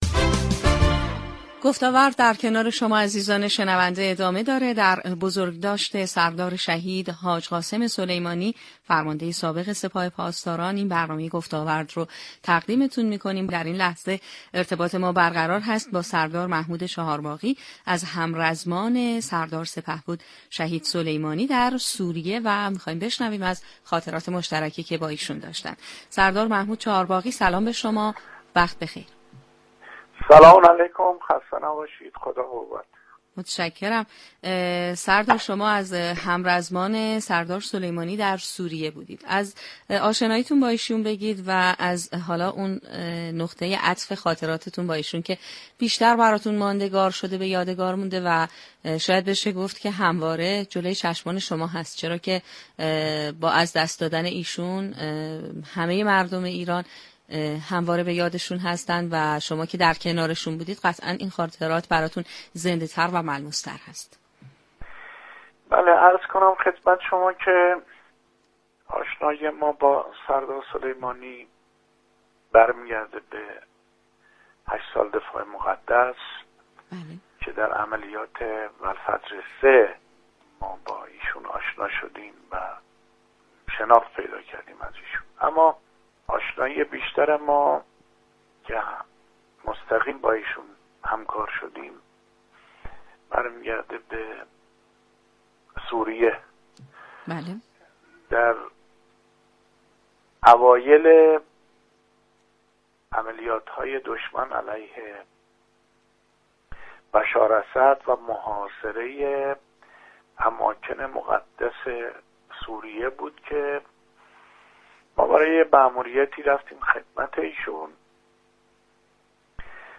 به گزارش پایگاه اطلاع رسانی رادیو تهران، سردار محمود چهارباغی در گفتگو با برنامه گفتاورد درباره سابقه آشنایی خود با سردار سلیمانی گفت: اولین سابقه آشنایی من با سردار سلیمانی به عملیات والفجر 3 باز می گردد.